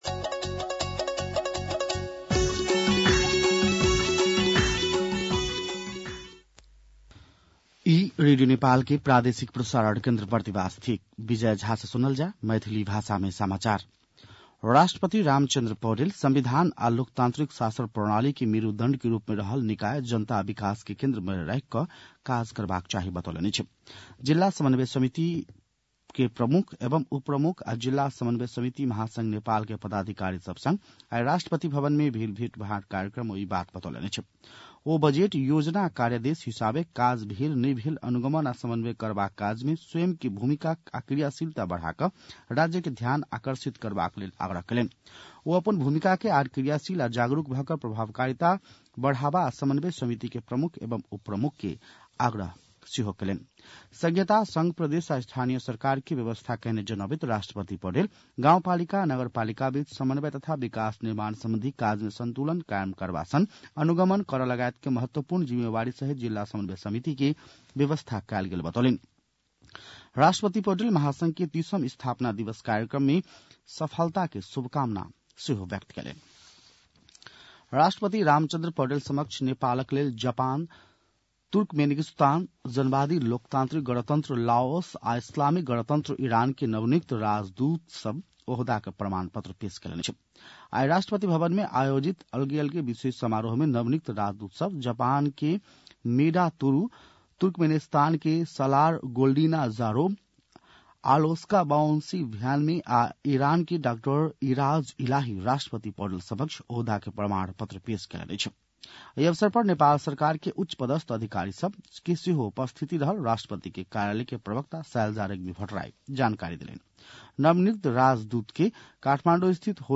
An online outlet of Nepal's national radio broadcaster
मैथिली भाषामा समाचार : ११ माघ , २०८१